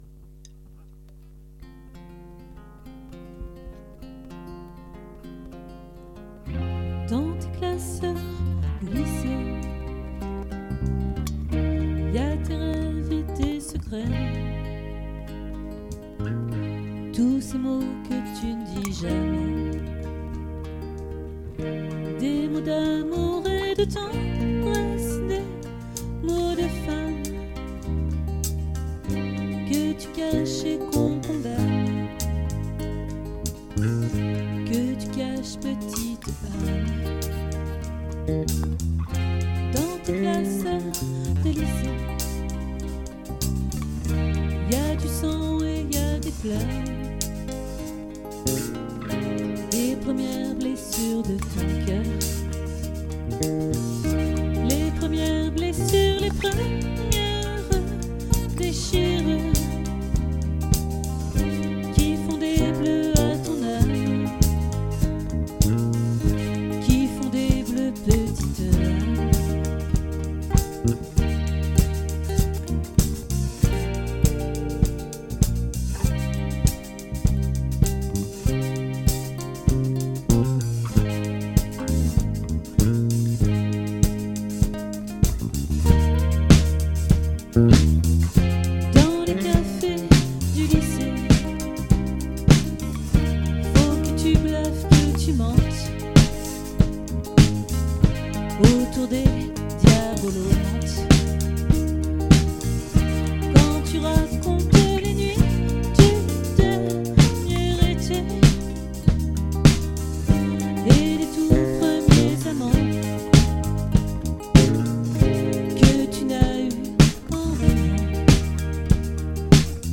🏠 Accueil Repetitions Records_2022_11_16_OLVRE